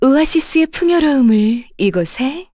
Laudia-Vox_Skill5_kr.wav